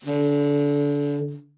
Index of /90_sSampleCDs/Giga Samples Collection/Sax/TEN SAX SOFT